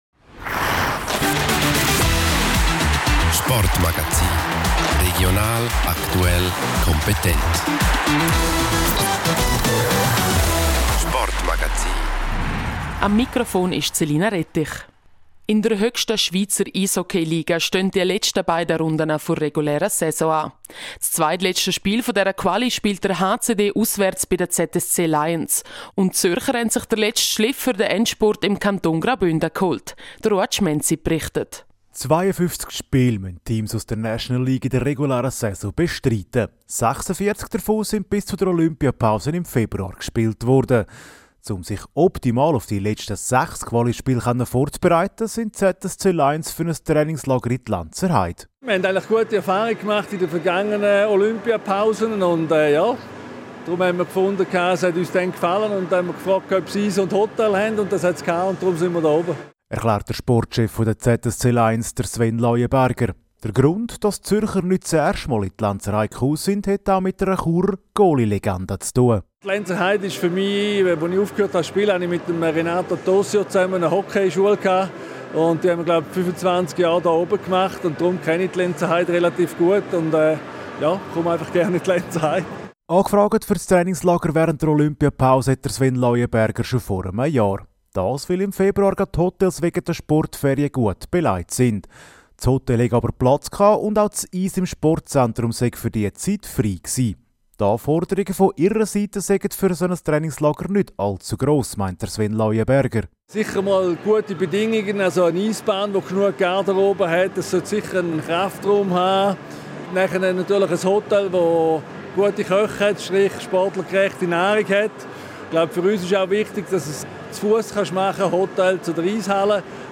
Dabei kommt es zum Derby. Eine kleine Stimmen-Vorschau.